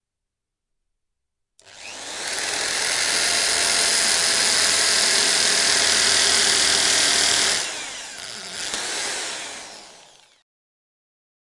机械声音 " 电钻 8
描述：使用数字录像机创建并在Reaper中处理。
标签： 机械 工人 工具 建筑 机械 钻头 电动工具 厂房 机器 厂房 木工 工人 工作 工具 电子 工业 建筑
声道立体声